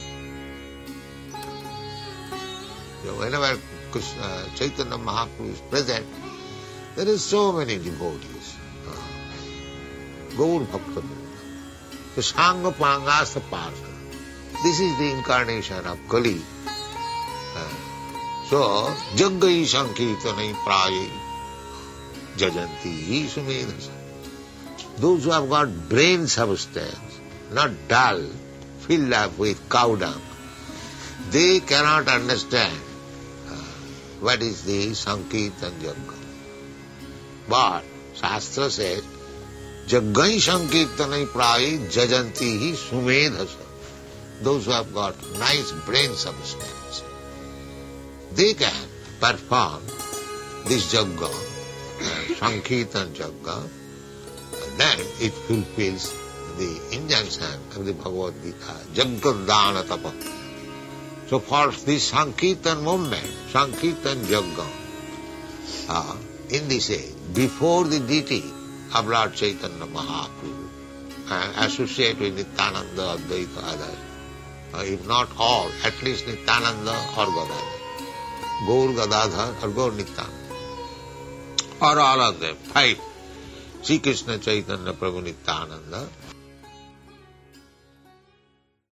(750310 – Lecture BG 07.02 – London)